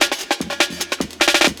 61 LOOP07.wav